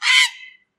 백로2
heron2.mp3